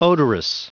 Prononciation du mot odorous en anglais (fichier audio)
Prononciation du mot : odorous